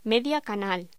Locución: Media canal
voz